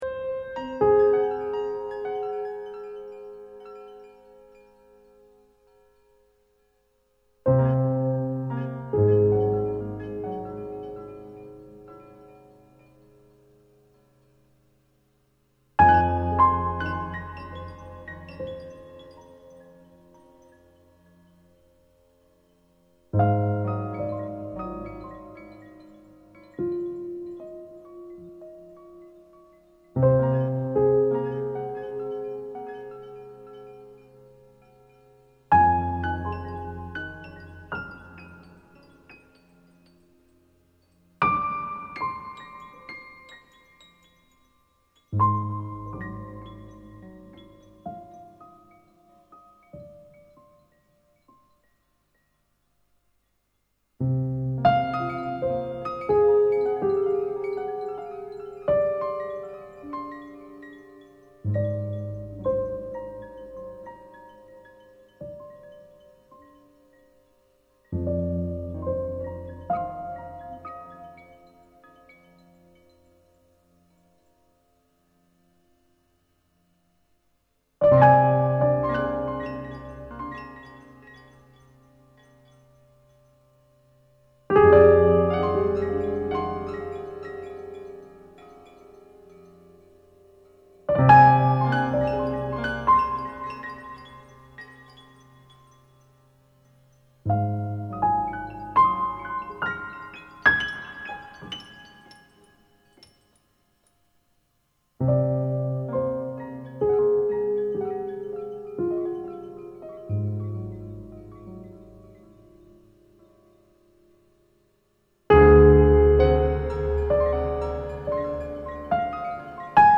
An amalgam of sound and music